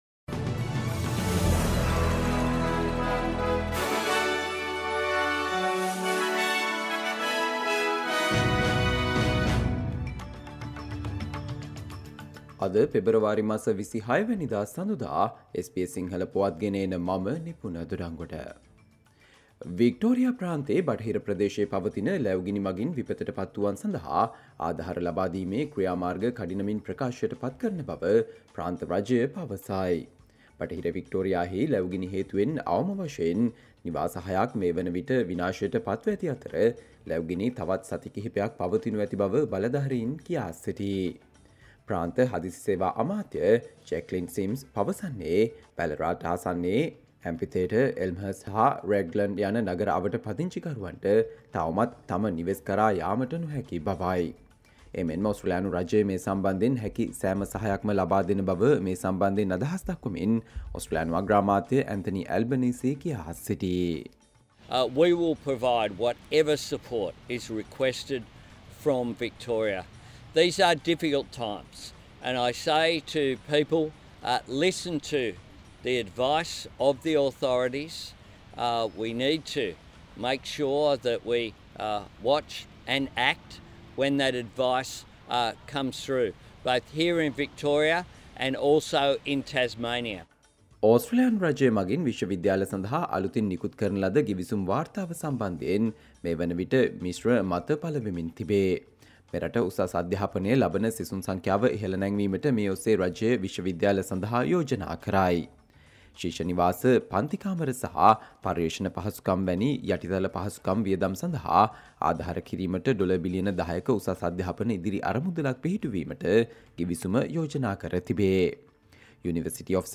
Australia news in Sinhala, foreign and sports news in brief - listen, Monday 26 February 2024 SBS Sinhala Radio News Flash